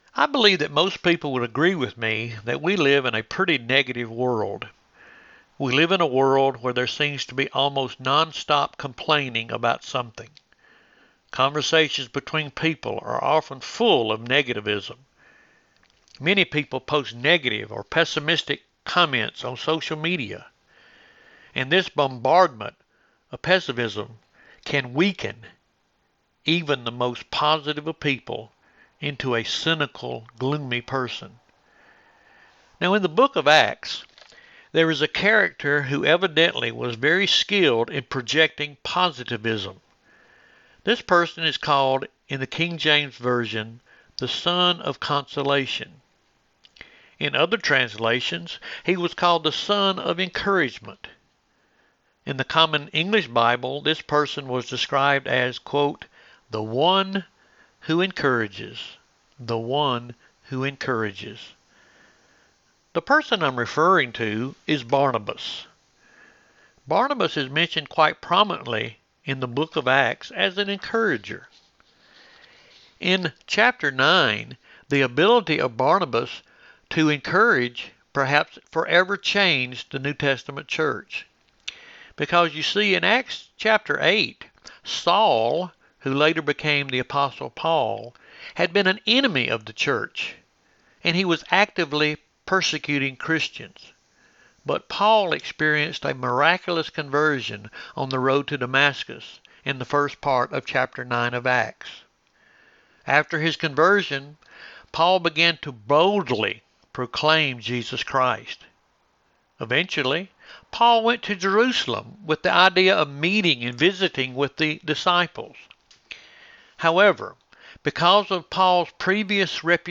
Lyerly United Methodist Church – Morning Devotion – Monday 8/29
Church Programs 0